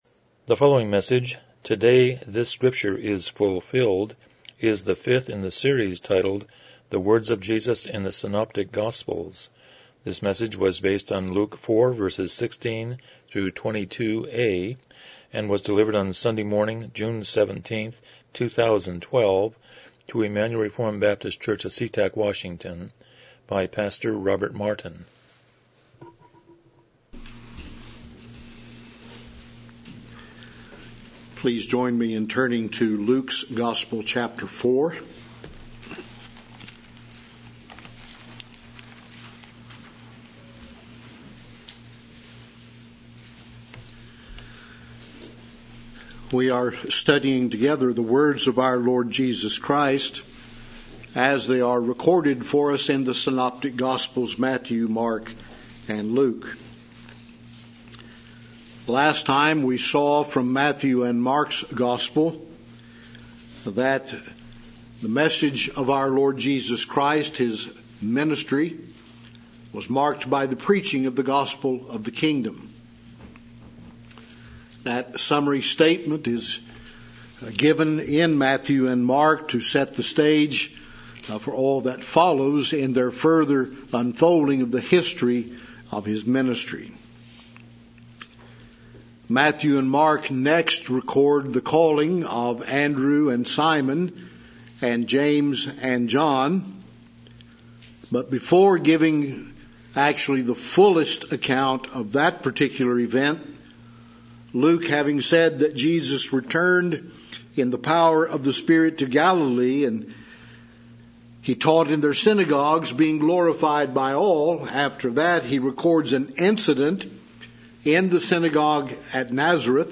Passage: Luke 4:16-22 Service Type: Morning Worship